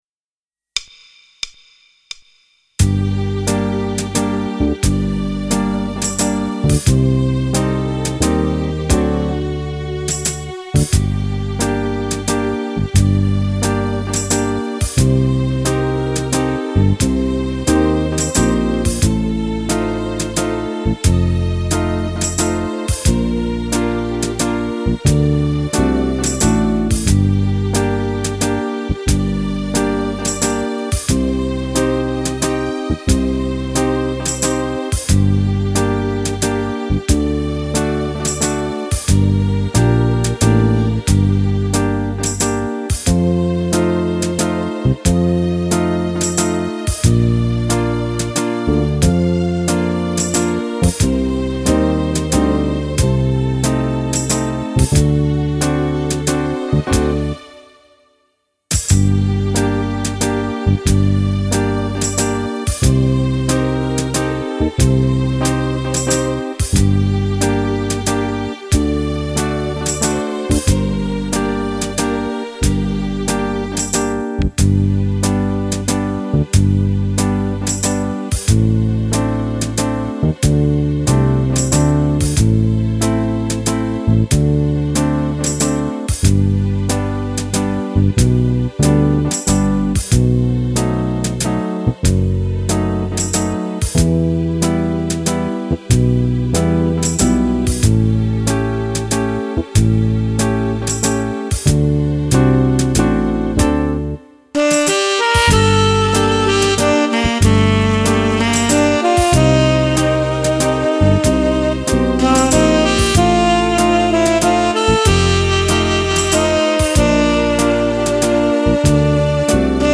Valzer lento
Fisarmonica